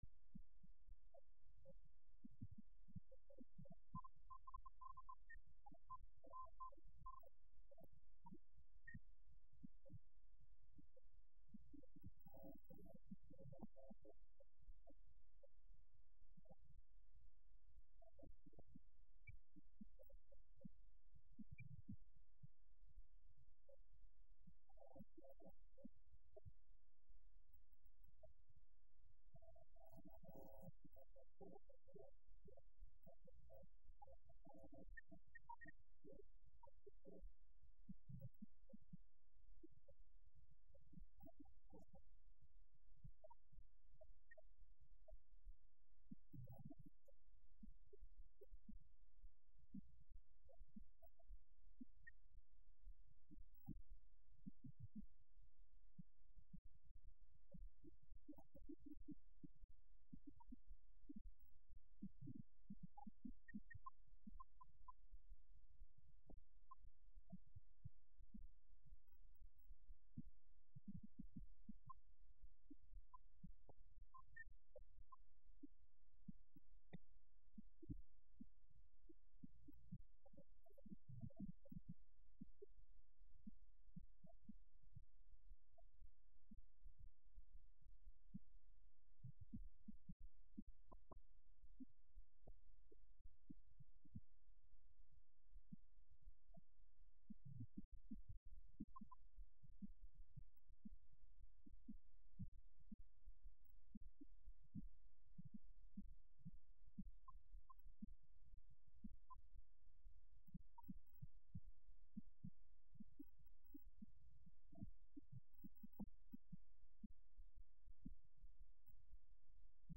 9/28/11 Wednesday Evening